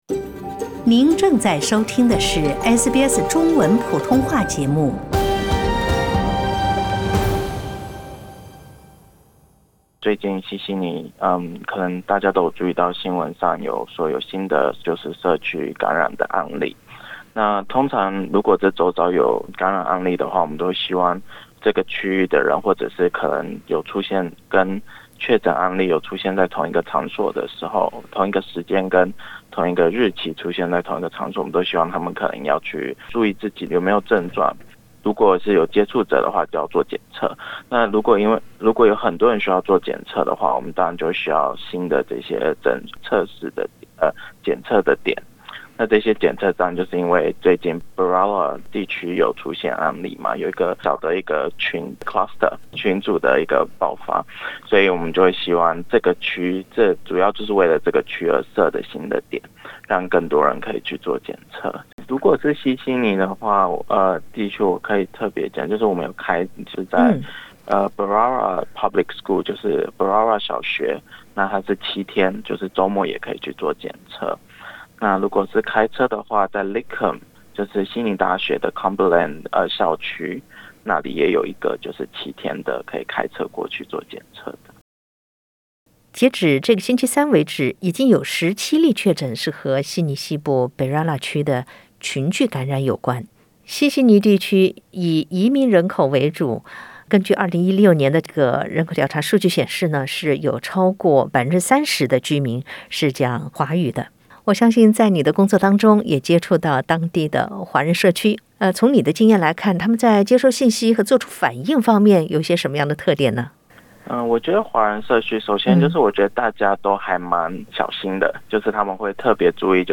在接受SBS普通话节目采访时